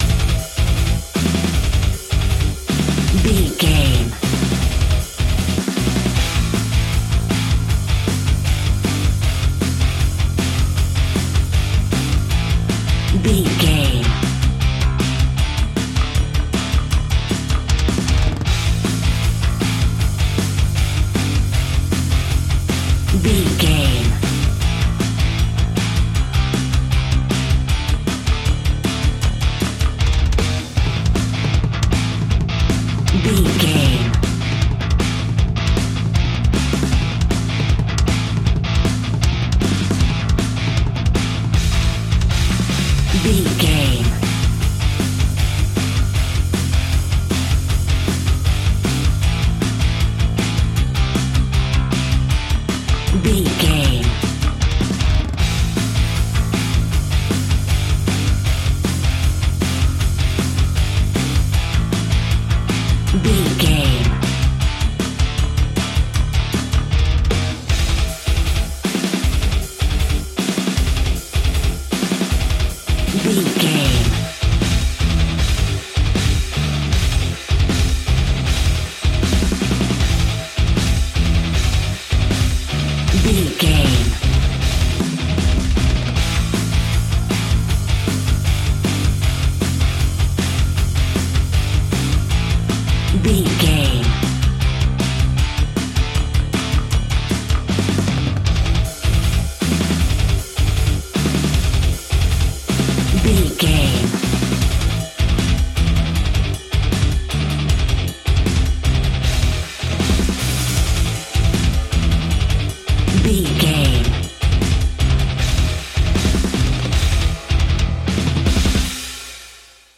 Epic / Action
Fast paced
In-crescendo
Aeolian/Minor
Fast
aggressive
powerful
heavy
bass guitar
electric guitar
drums